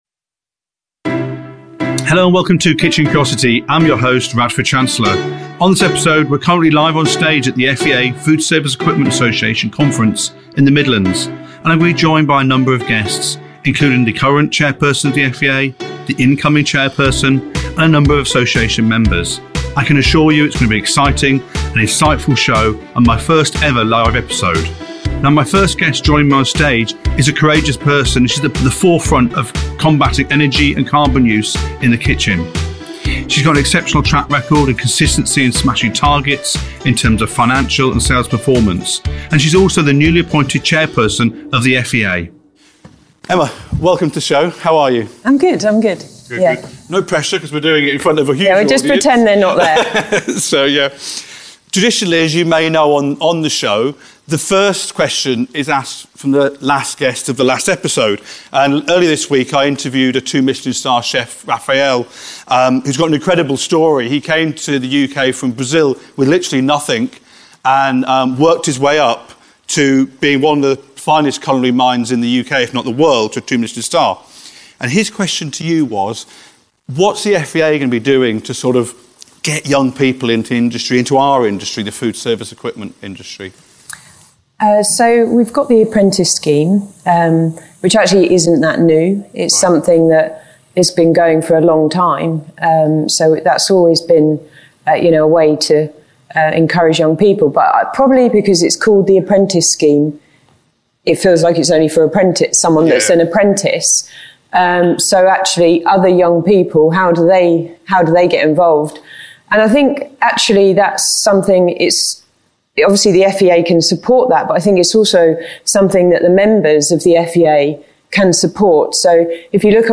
at the recent FEA Conference